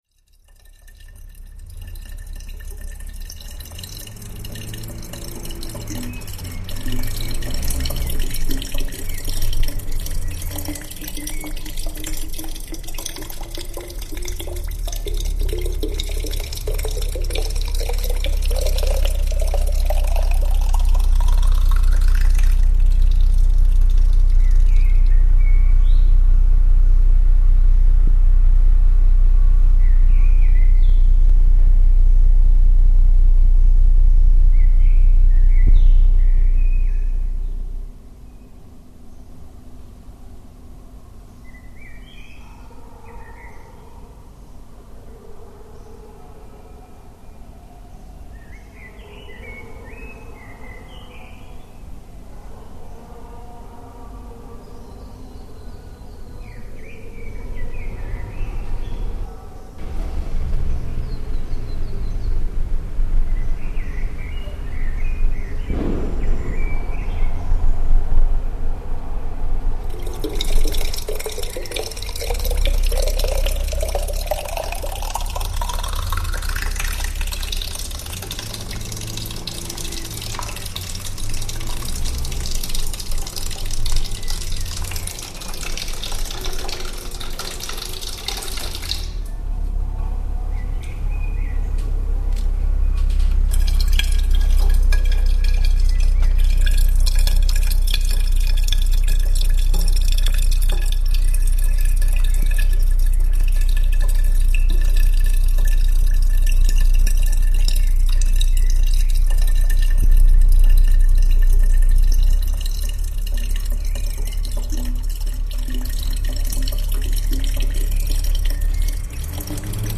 zahlenkomposition nr. III als partitur für 5 player, 25 minuten spielzeit
die 45 minütige aufnahme wurde in 5 teile geschnitten. jeder player spielt einen teil der aufnahme (loop).
mp3, teil des beitrags zum festival am 25. november 2006 im monty, friedrichshain. aufnahmen vom flughafen tegel am 27. april 2001.